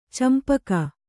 ♪ campaka